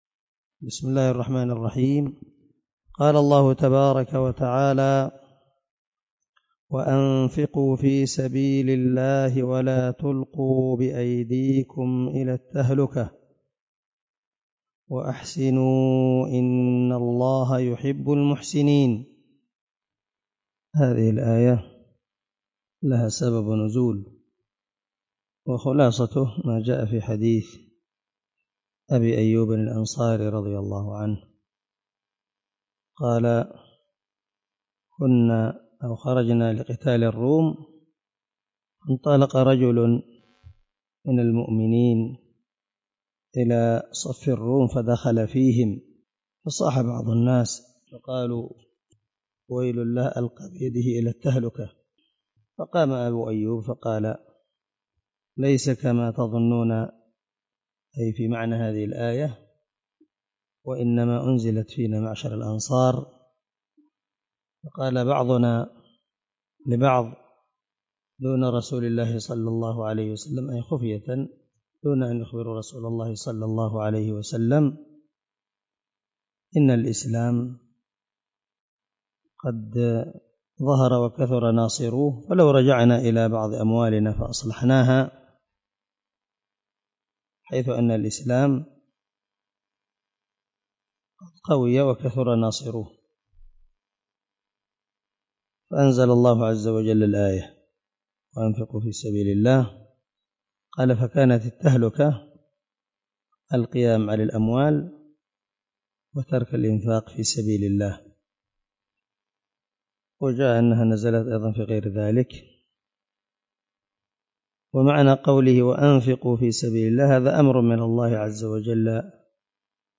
090الدرس 80 تفسير آية ( 195 ) من سورة البقرة من تفسير القران الكريم مع قراءة لتفسير السعدي